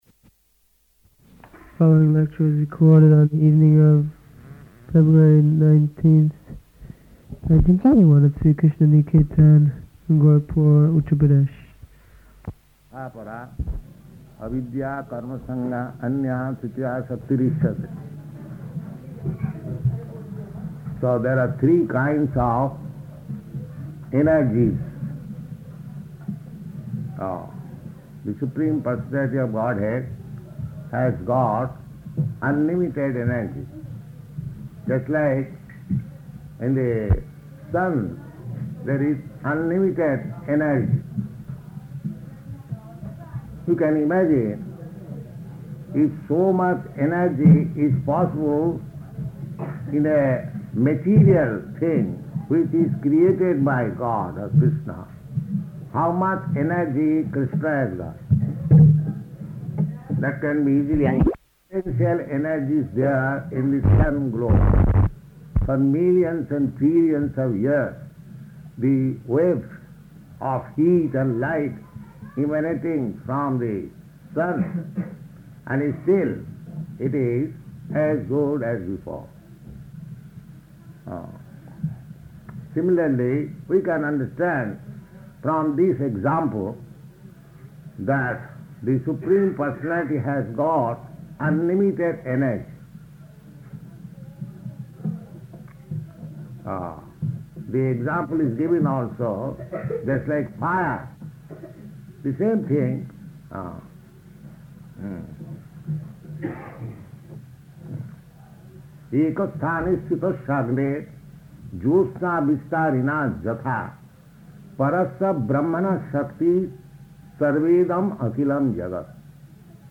Location: Gorakphur